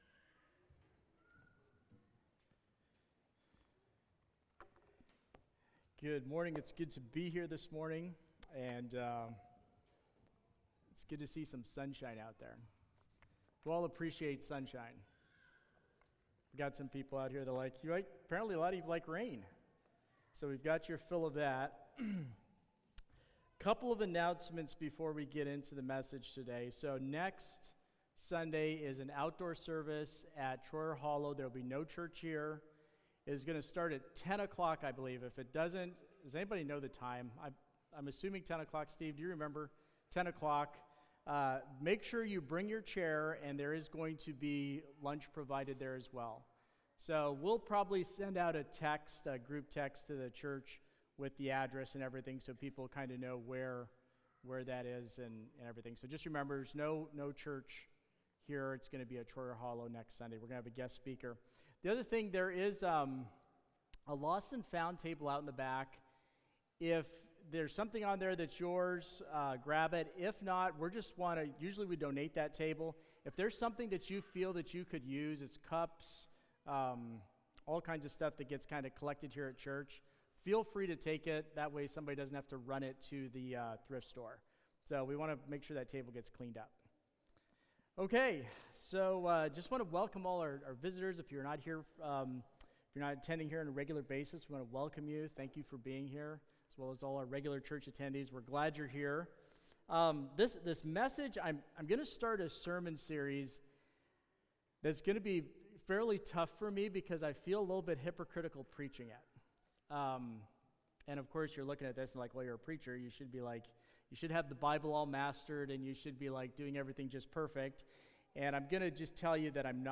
Sermons | Christian Life Mennonite